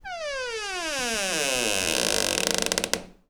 door_creak_long_04.wav